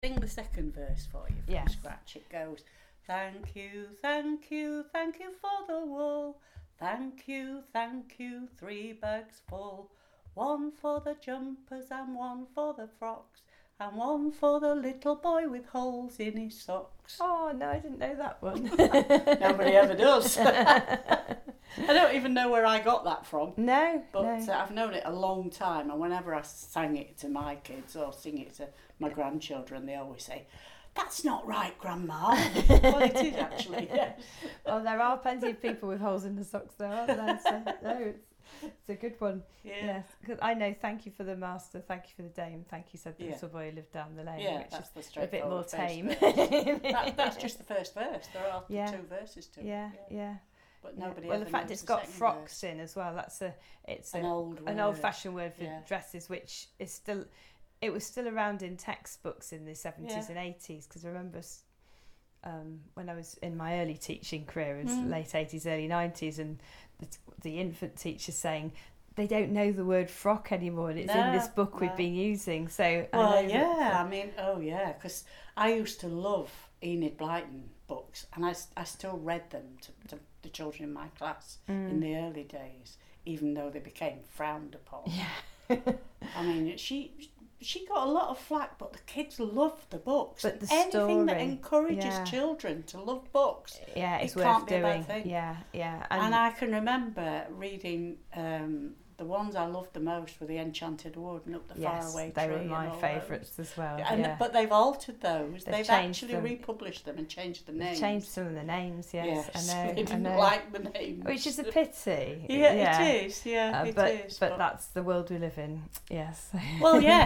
sang to me and then a short conversation about the words e.g., the word 'frock'.